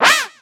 Cri de Gruikui dans Pokémon X et Y.